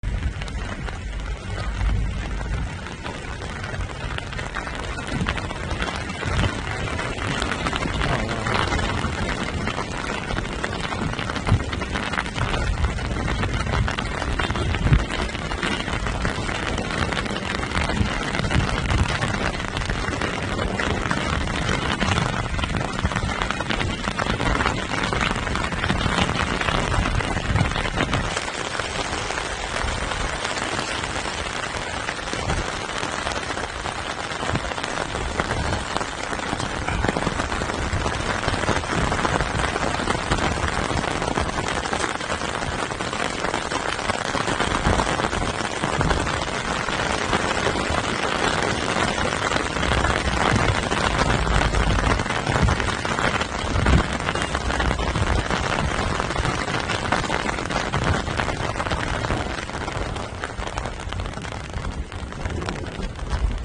בגשם שוטף עשרות ישראלים ממתינים לארונות ארבעת החללים
חדשות ישראל